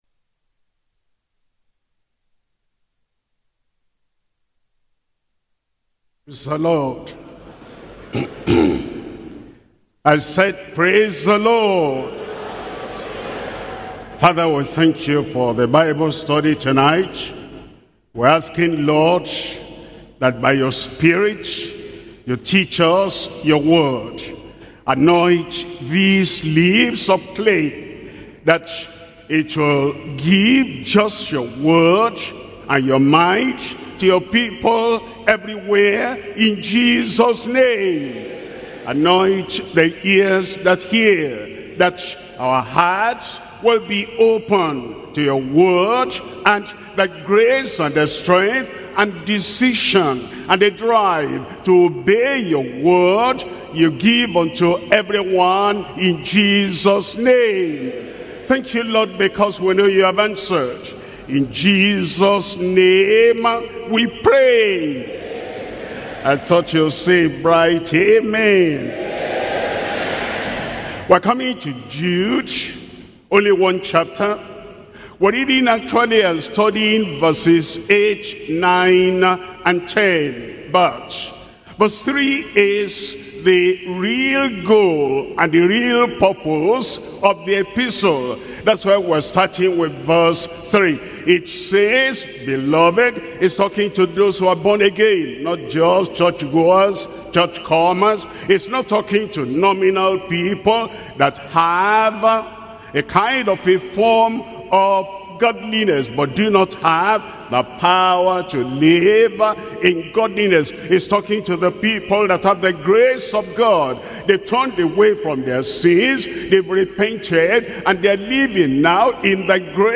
Sermons - Deeper Christian Life Ministry
Bible Study